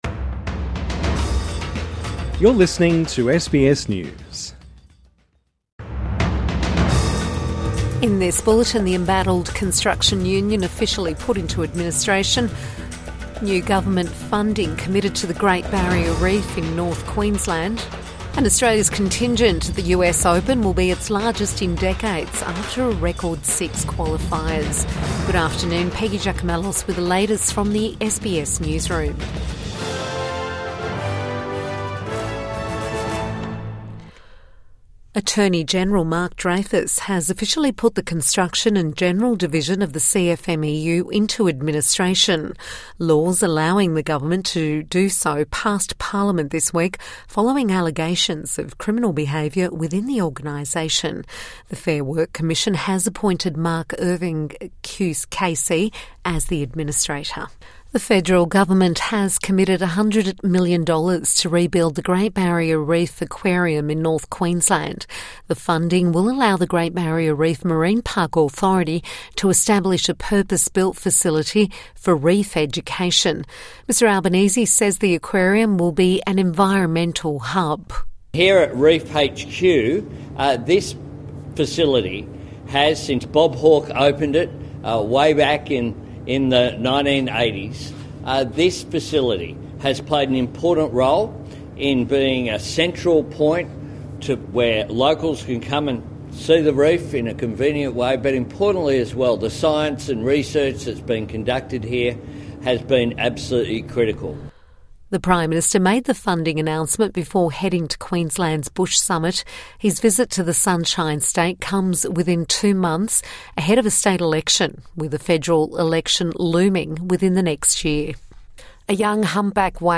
Midday News Bulletin 23 August 2024